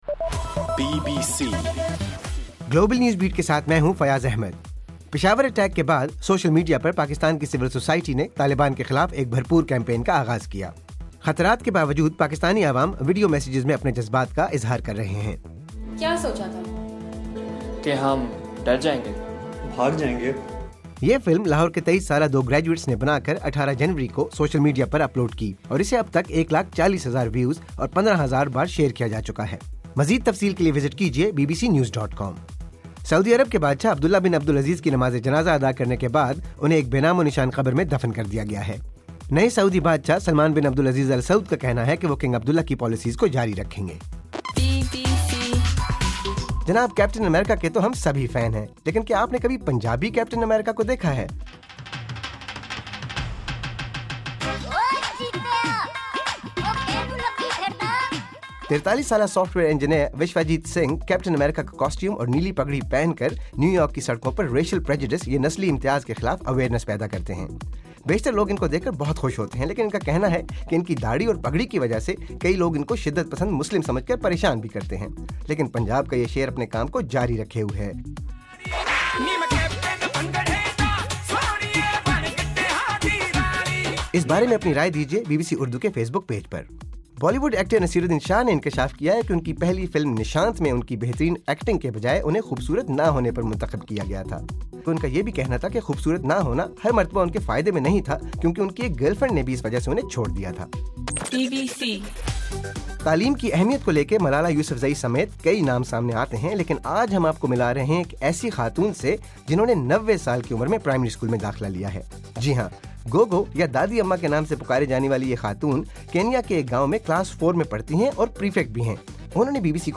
جنوری 23: رات 12 بجے کا گلوبل نیوز بیٹ بُلیٹن